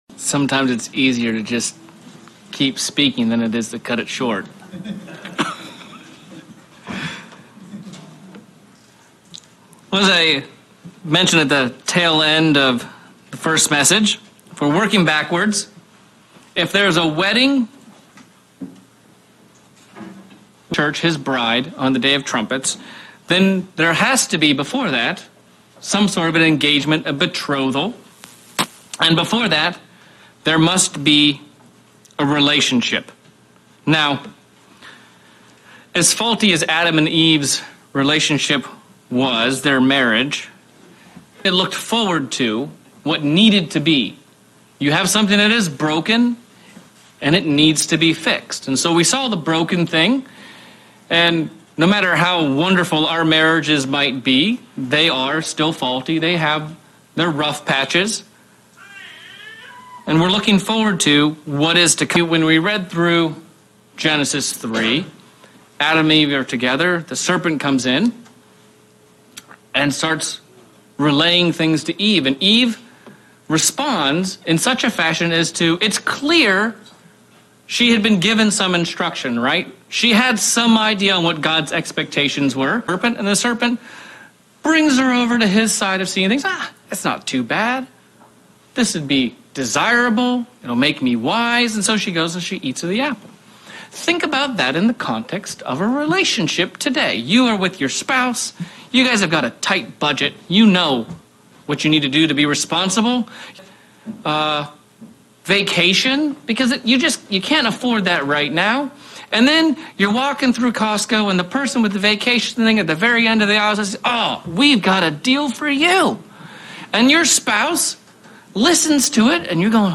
Sermon examining our relationship with God, our wedding garments and what we are dong to prepare to be ready for the upcoming wedding with Jesus Christ